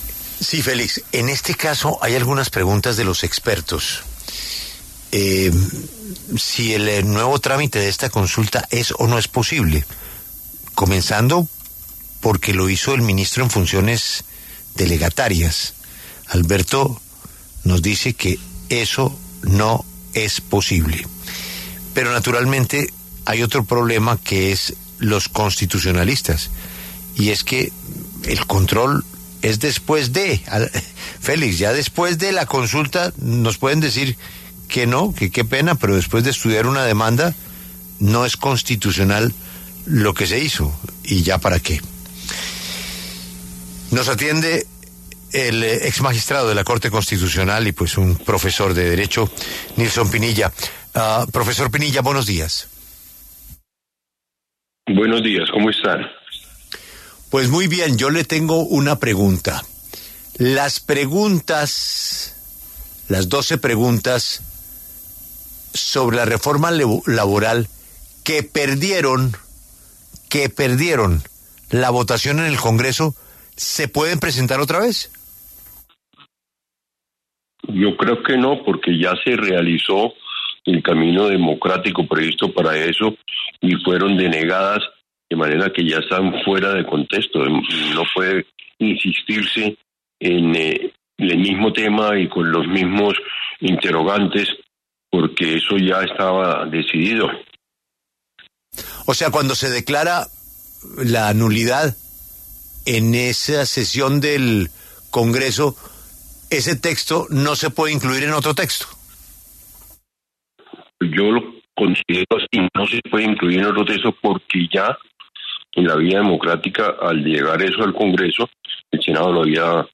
En entrevista con La W, el exmagistrado de la Corte Constitucional Nilson Pinilla, se refirió a las dudas jurídicas que han aparecido ante la radicación de la nueva consulta popular. En su opinión, el Gobierno no podía presentar las mismas doce preguntas de índole laboral que ya fueron rechazadas por el Senado de la República la semana anterior.